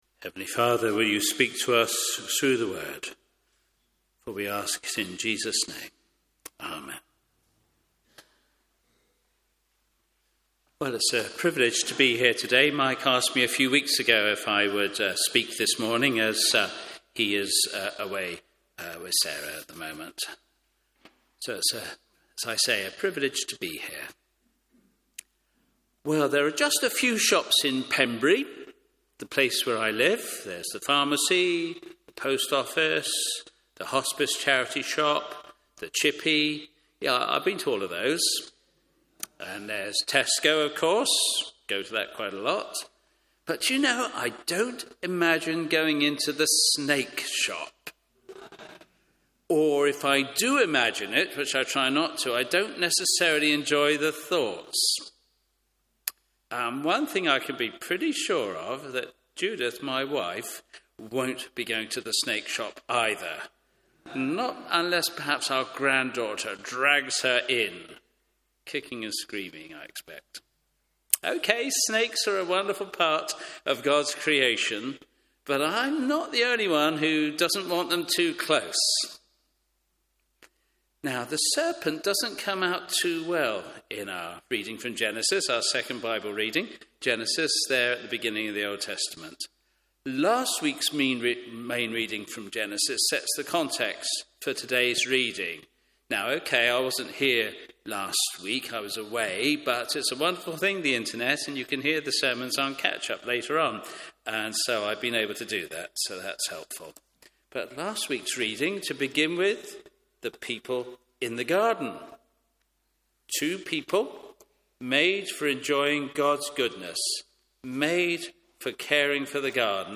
Media for Morning Service on Sun 22nd Jun 2025 10:30 Speaker
Genesis Theme: Sermon In the search box please enter the sermon you are looking for.